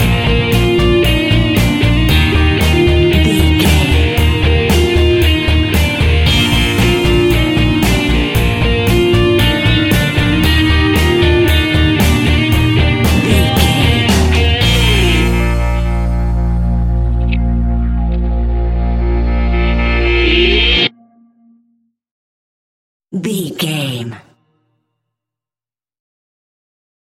Ionian/Major
B♭
pop rock
indie pop
fun
energetic
uplifting
guitars
bass
drums
piano
organ